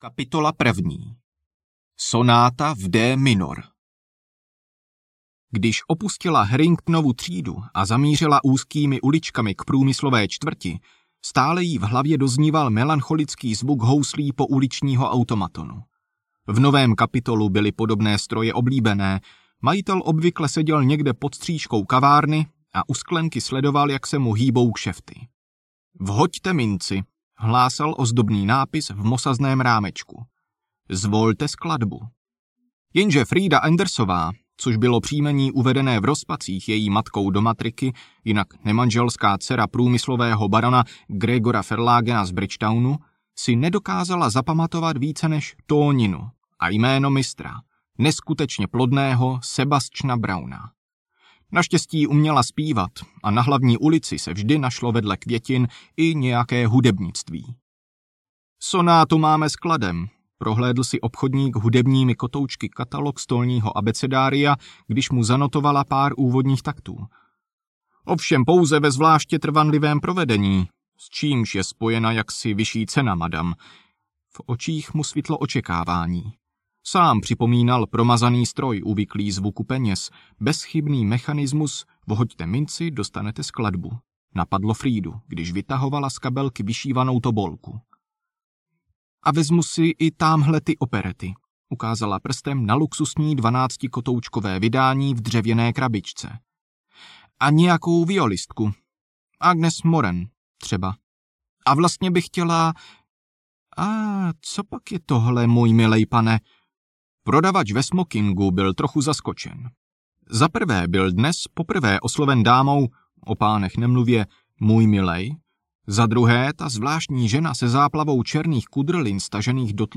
Interpret:
Kategorie: Dobrodružné, fantasy
Každý uživatel může po zakoupení audioknihy daný titul ohodnotit, a to s pomocí odkazu zaslaný v mailu.